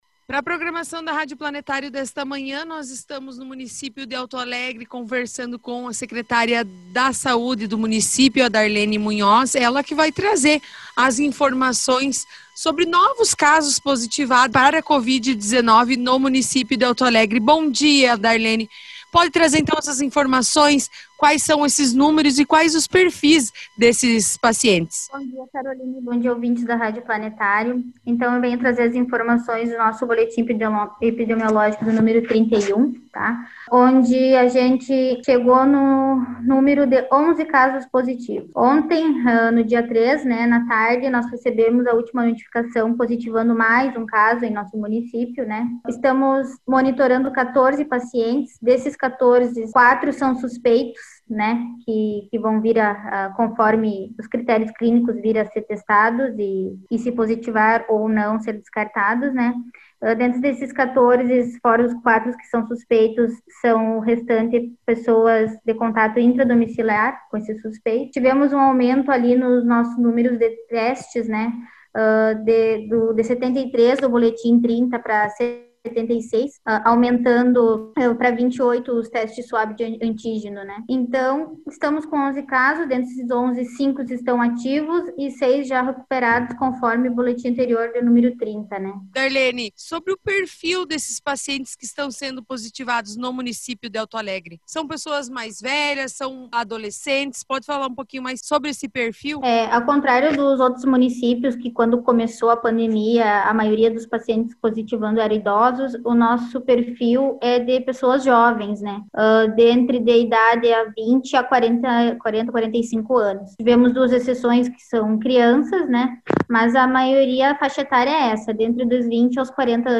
A secretária de saúde Darlene Munhoz em reportagem a nossa emissora dá mais detalhes e enfatiza que grande parte das pessoas que está com a doença tem entre 20 a 42 anos de idade.
Reportagem